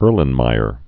(ûrlən-mīər, âr-)